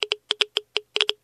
geiger_6.ogg